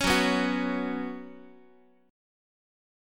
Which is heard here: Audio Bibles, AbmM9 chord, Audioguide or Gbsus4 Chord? Gbsus4 Chord